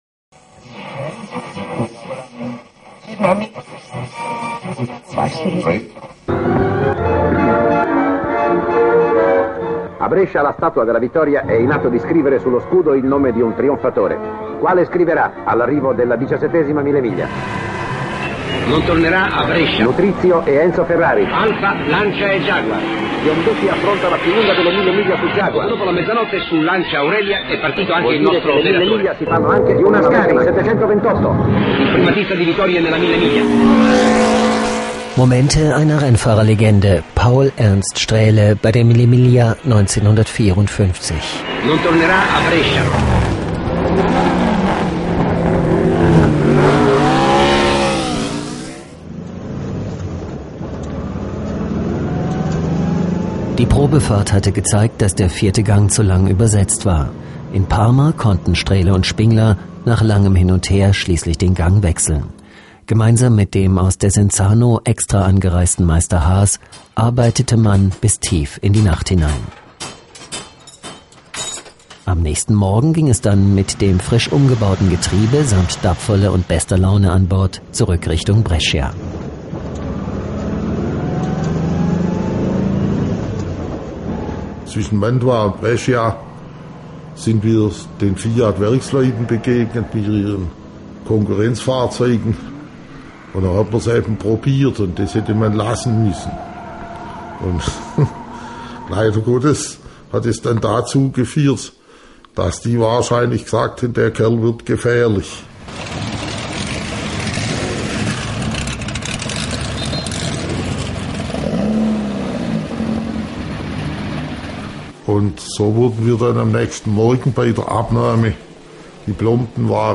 Audioproduktionen
Hörbuchbeispiel-Mille-Miglia-1954.mp3